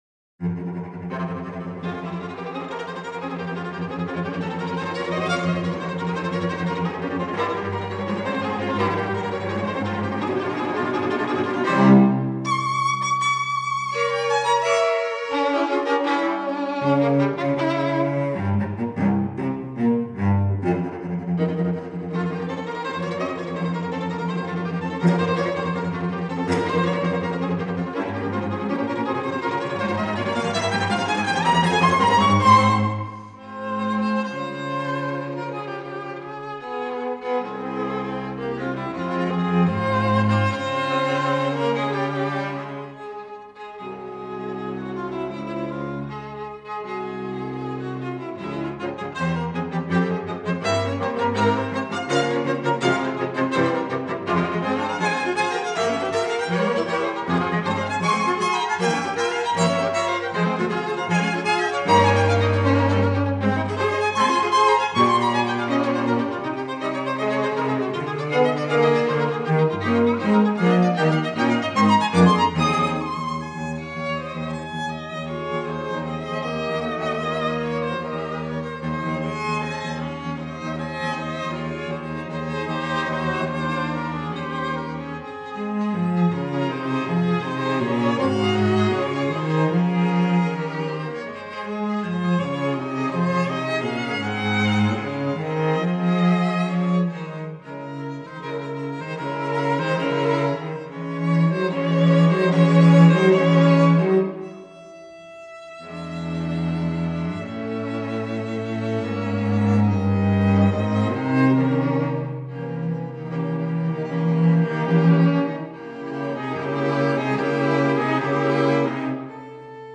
String Quartet
Style: Classical
Attribution: Audio: Musopen String Quartet Copyright: Creative Commons 3.0 This piece is included in the following : Felix Mendelssohn Sheet Music String Quartet Sheet Music COMMENTS + Your Comment Load More Info You mi
string-quartet-6-op-80.mp3